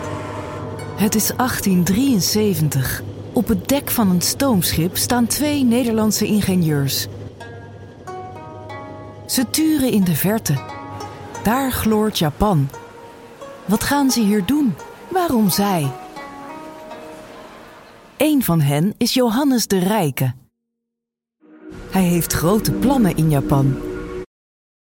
Documentaries
I am a professional Dutch Voice-over with a clear, warm and fresh voice.